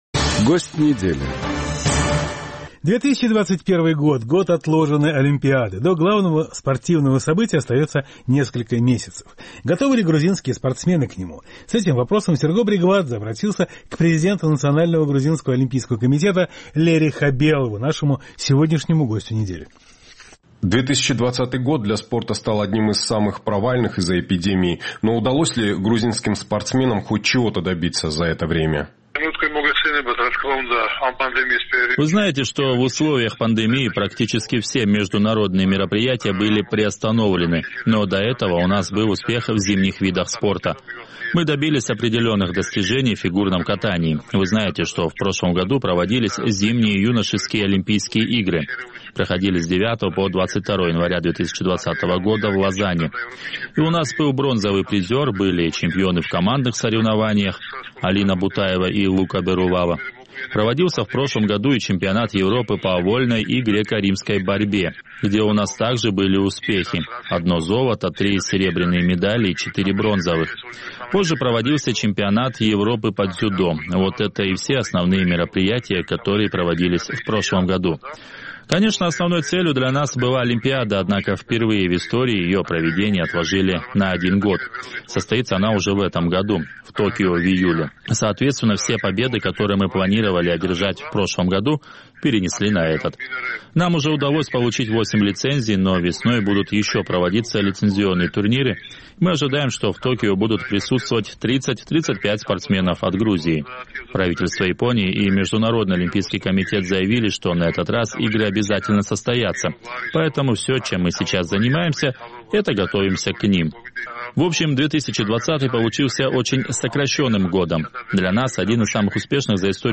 Гость недели
Президент Национального олимпийского комитета Грузии Лери Хабелов